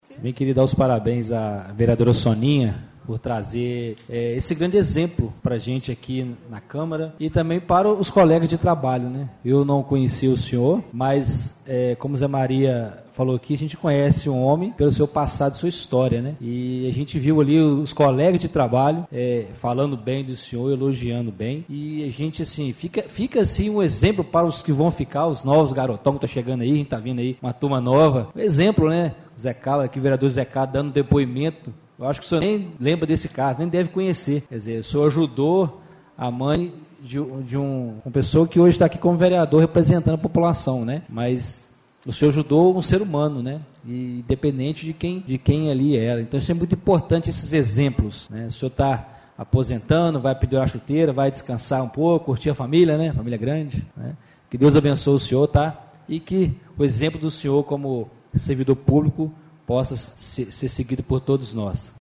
Presidente da Câmara Municipal de Ubá